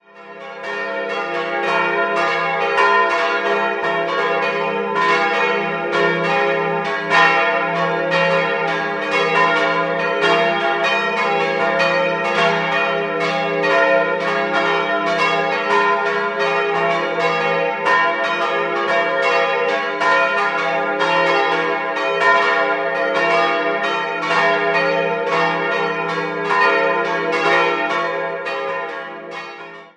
Michaelsglocke f'+0 697 kg 1951 Johann Hahn, Landshut Josefsglocke as'+0 403 kg 1951 Johann Hahn, Landshut Georgsglocke b'-2 257 kg 1951 Johann Hahn, Landshut Marienglocke des''+0 285 kg 16. Jh. "Limherr Reiner", München